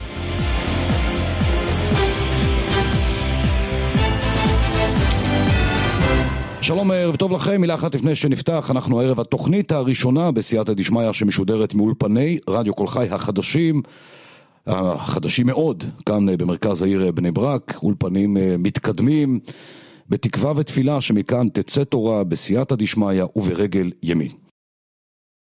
"מכאן תצא תורה": כך נפתחו השידורים מהמתחם החדש